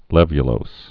(lĕvyə-lōs, -lōz)